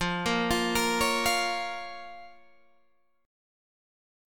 Fsus4#5 Chord